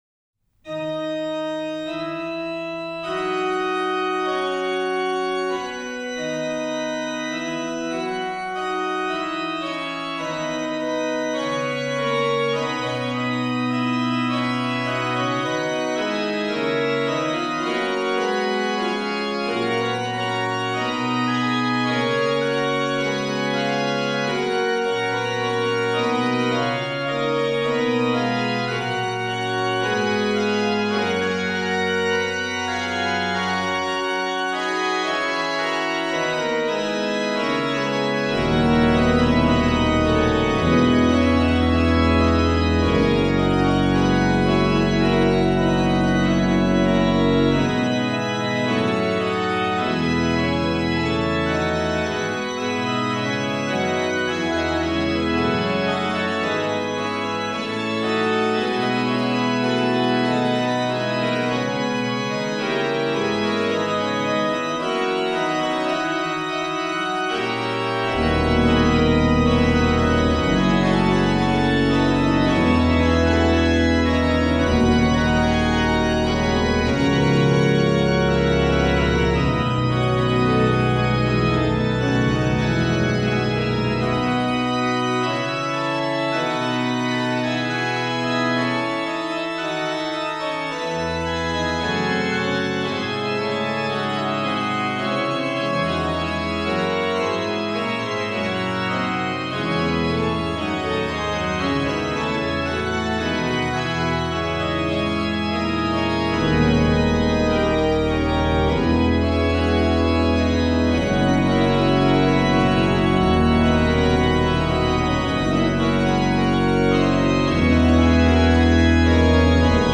Subtitle   Canto fermo in Basso; Cum Organo pleno
Registration   HW: Por16, Pr8, Por8, Oct4, Oct2, Mix